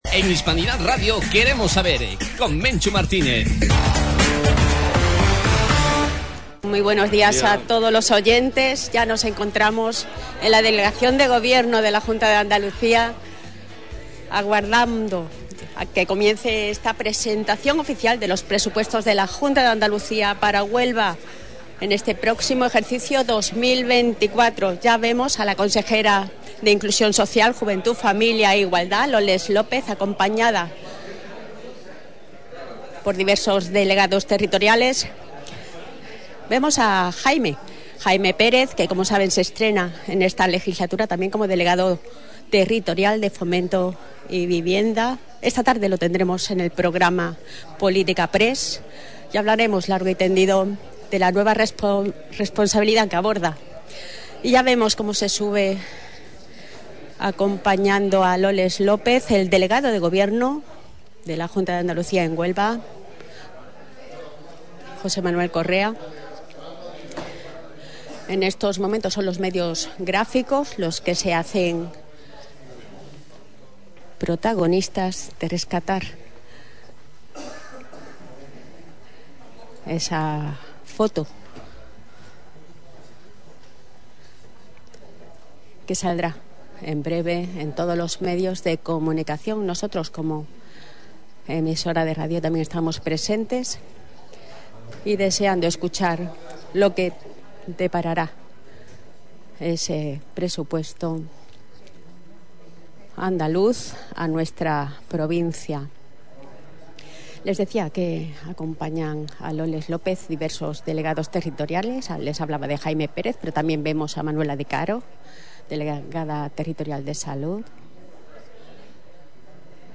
Emisión en directo desde la delegación de la Junta de Andalucía en Huelva. La consejera de Inclusión, Loles López, acompañada por el delegado del Gobierno de la Junta en Huelva, José Manuel Correa, han presentado las cuentas para la provincia, que contemplan inversiones por valor de 342,7 millones.